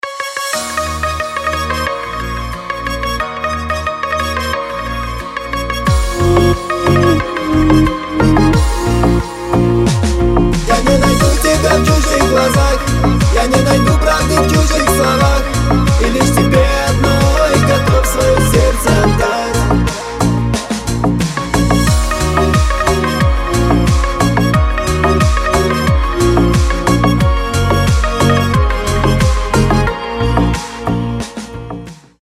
• Качество: 320, Stereo
мужской голос
зажигательные
кавказские